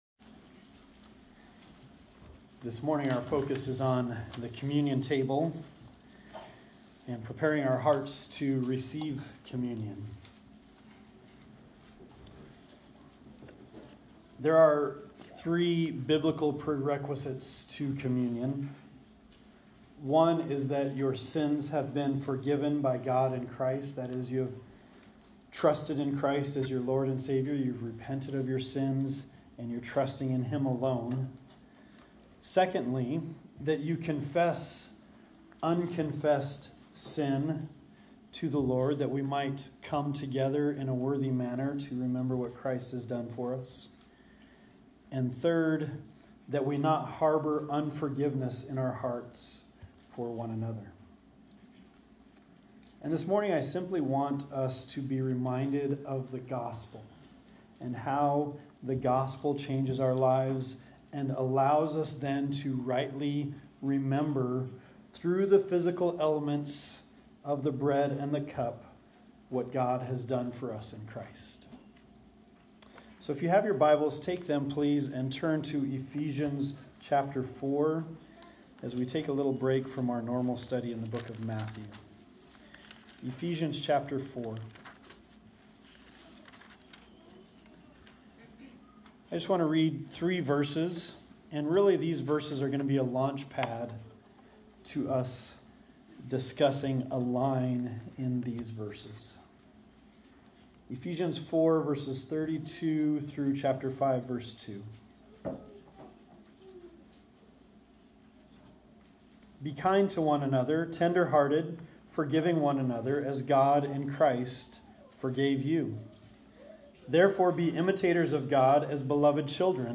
Communion Meditation: Forgiveness